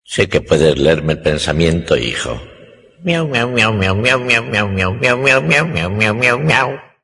Fragmento de la exitosa serie de dibujos animados, Los Simpsons. Homer sabe que su hijo Bart puede leerle el pensamiento, así que le dice algo interesante…